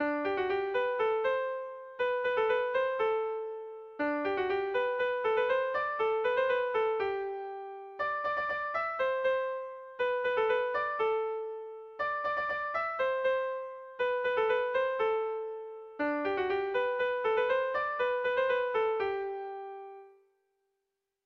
Irrizkoa
Hamarrekoa, txikiaren moldekoa, 5 puntuz (hg) / Bost puntukoa, txikiaren moldekoa (ip)
ABDE...